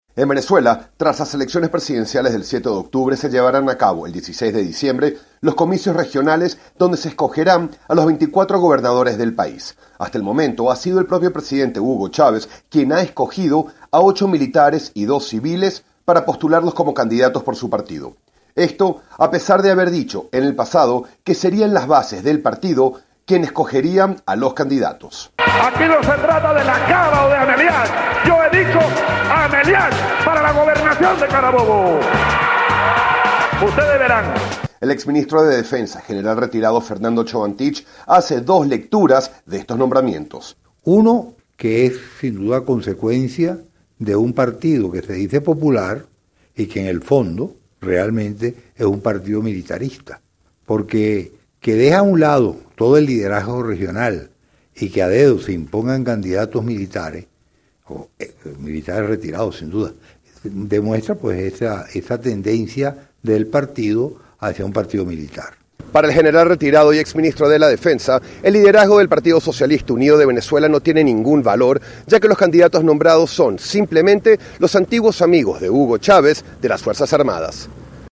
desde Caracas para Radio Martí.